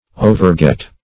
Search Result for " overget" : The Collaborative International Dictionary of English v.0.48: Overget \O`ver*get"\, v. t. 1. To reach; to overtake; to pass.